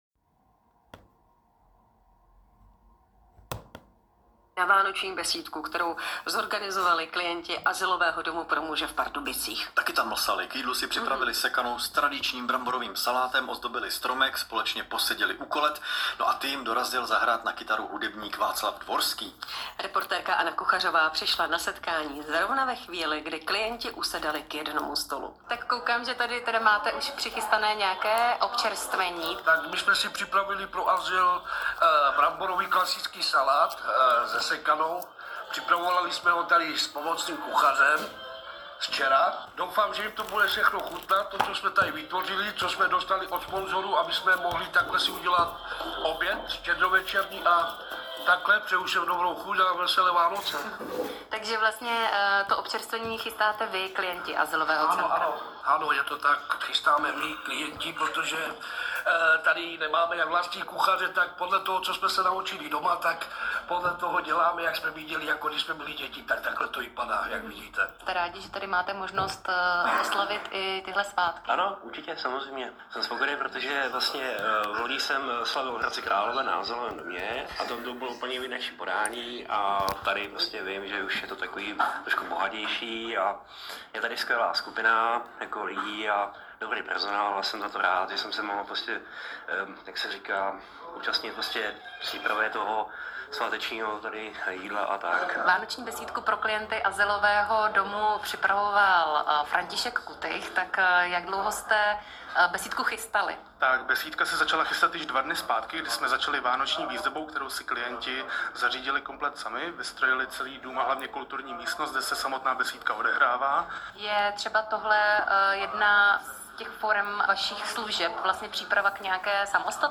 Reportáž 24.12. Český… (3.08 MB)